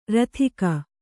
♪ rathika